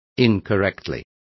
Complete with pronunciation of the translation of incorrectly.